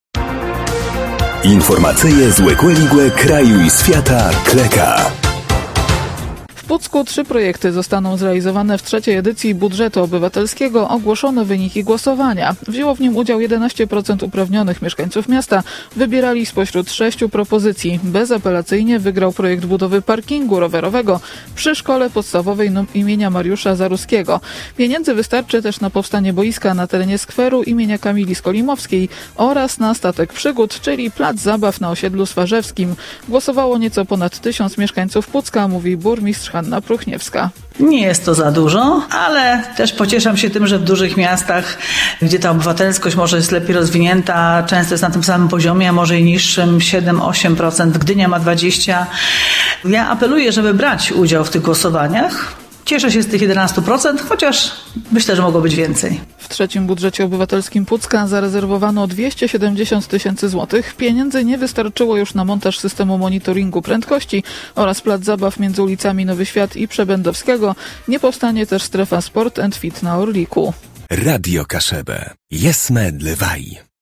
– Głosowało nieco ponad tysiąc mieszkańców Pucka – mówi burmistrz Hanna Pruchniewska.